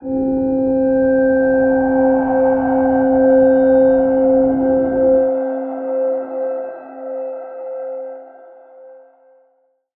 G_Crystal-C5-mf.wav